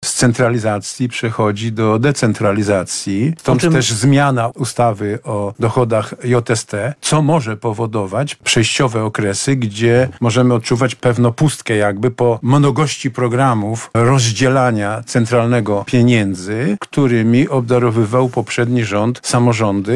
Dokonania rządu Donalda Tuska na antenie Radia Lublin oceniali radni Sejmiku Województwa Lubelskiego, którzy byli gośćmi programu „Środek tygodnia”.